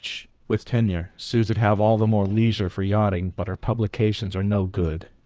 text-to-speech
The output will be a URL linking to the generated audio file of the synthesized speech.